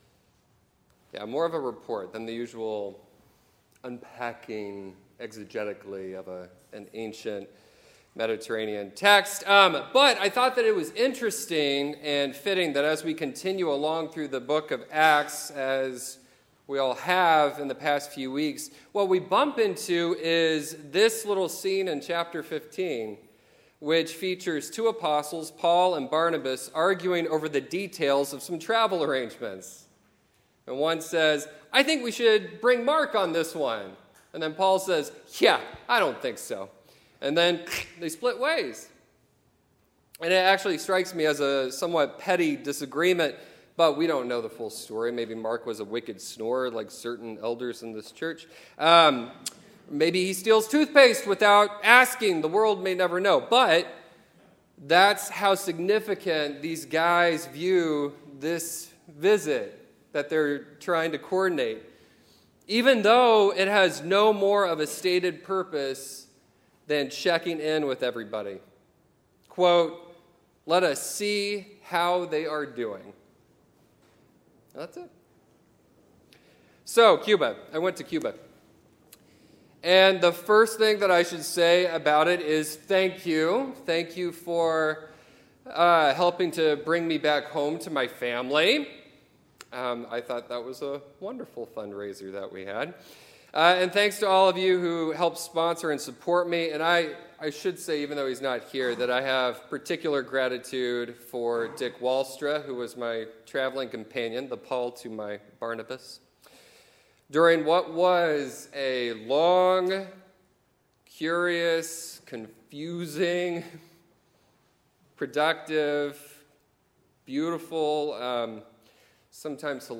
Scripture Lesson